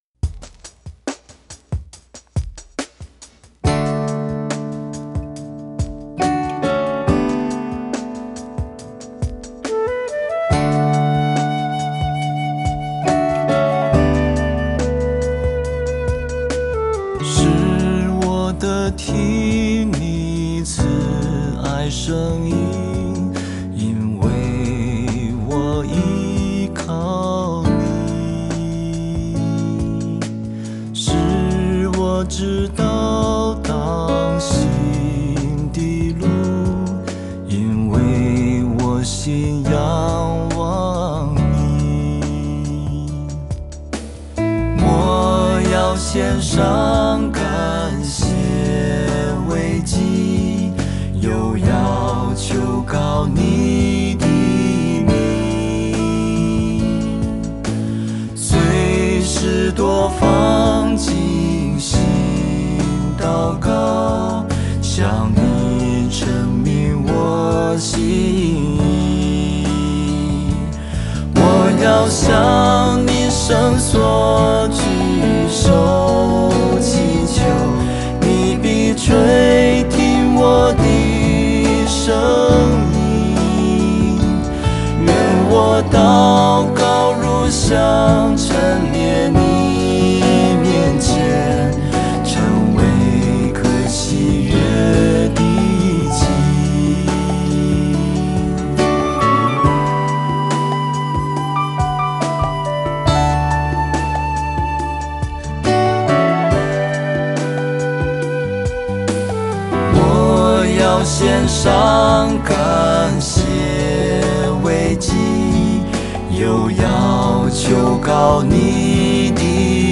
mp3 原唱音樂
flute